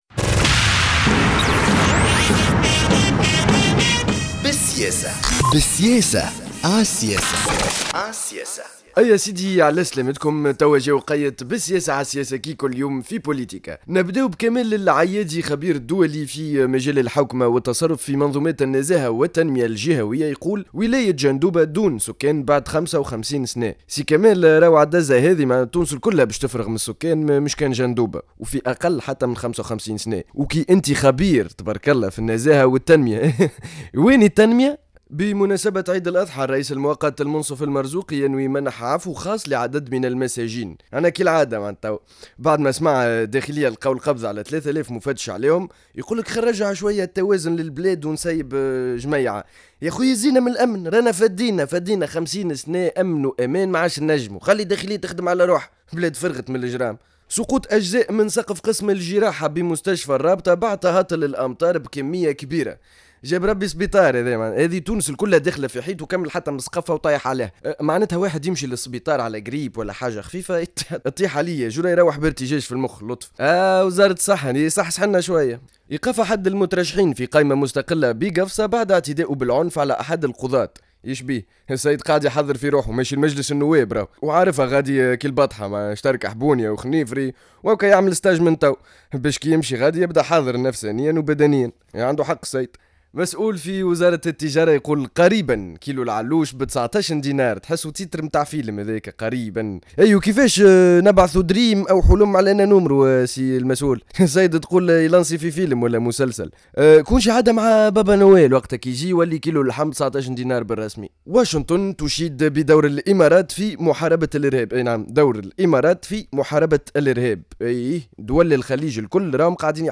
نشرة أخبار منتصف النهار ليوم الثلاثاء 07-10-14